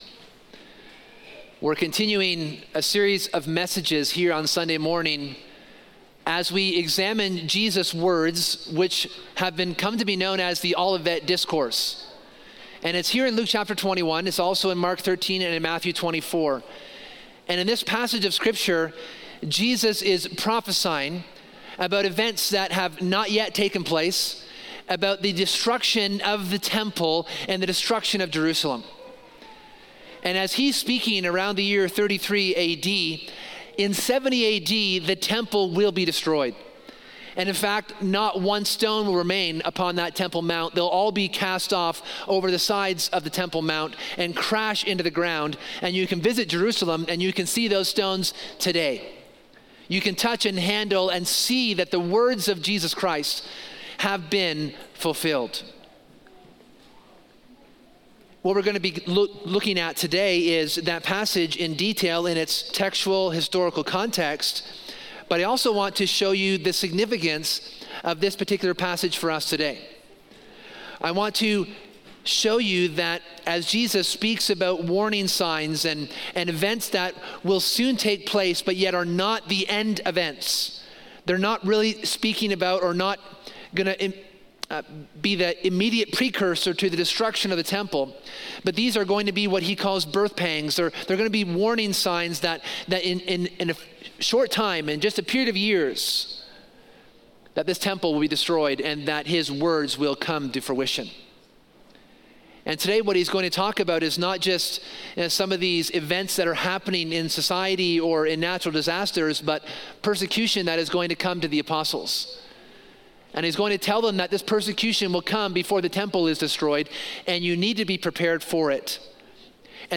In this second sermon on the Olivet Discourse in Luke 21, we’re reminded of the suffering the apostles faced between the time of Jesus and the temple’s destruction.